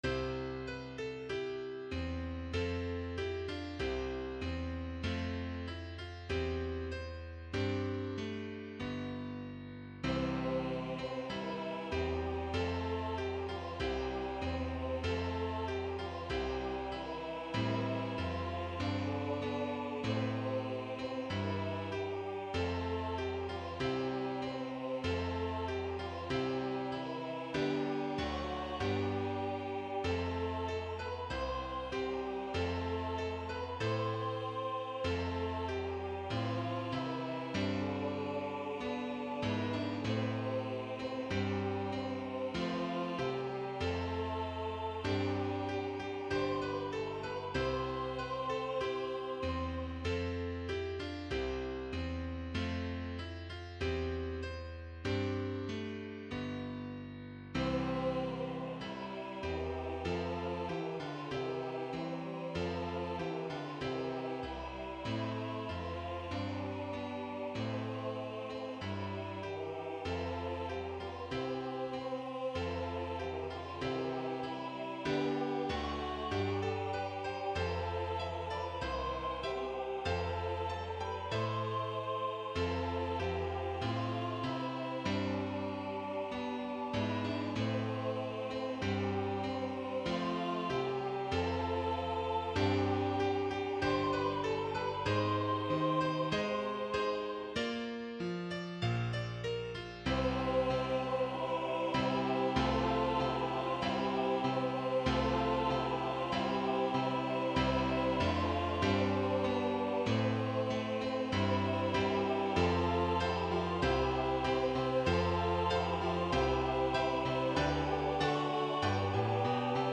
SATB, Youth Choir Mixed Or Unison
Voicing/Instrumentation: SATB , Youth Choir Mixed Or Unison